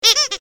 clock04.ogg